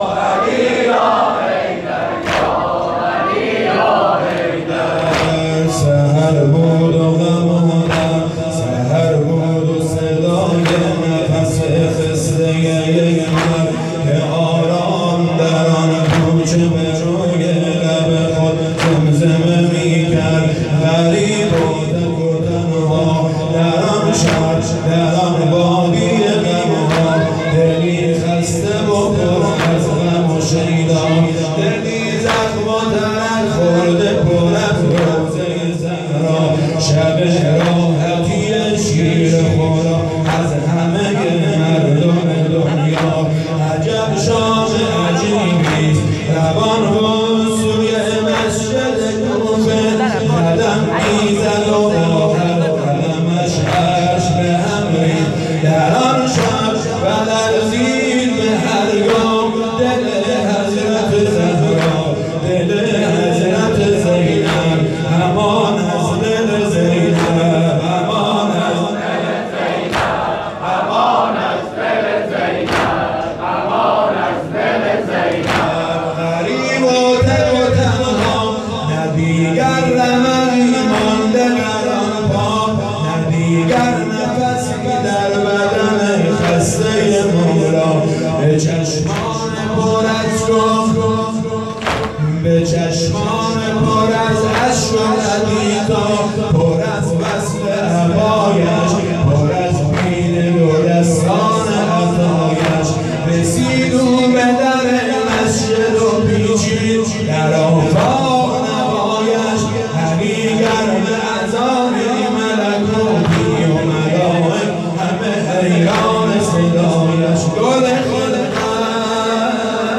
شب اول قدر (19رمضان) 1440